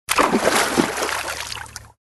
На этой странице собраны натуральные звуки шагов по лужам: от легкого шлепанья до энергичного хлюпанья.
Прыгнул в глубокую лужу